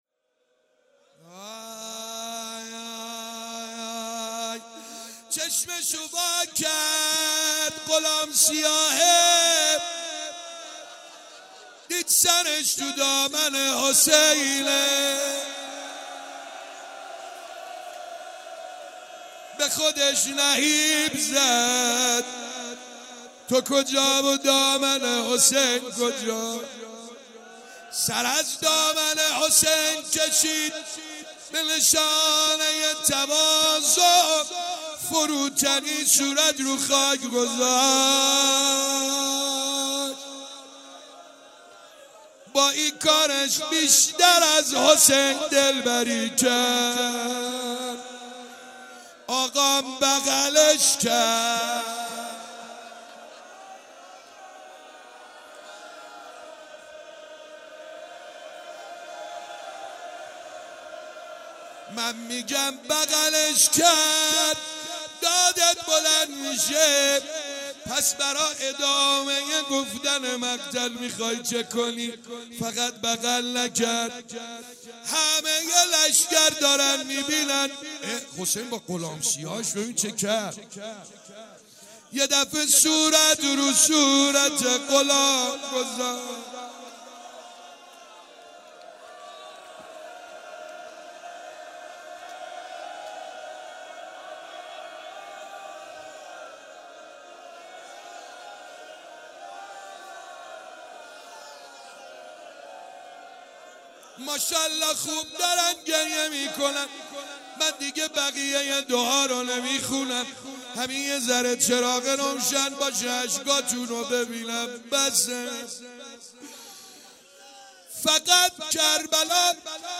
شب 18 ماه مبارک رمضان _روضه_غلام سیاه امام حسین علیه السلام
ماه رمضان سعید حدادیان روضه مداحی